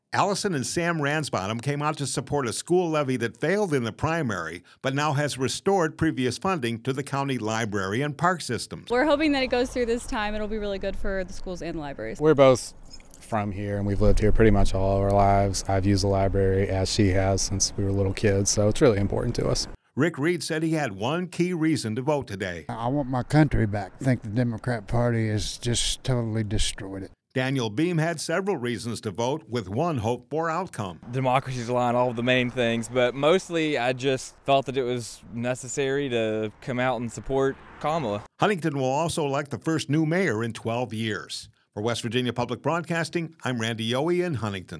talks to voters in Cabell County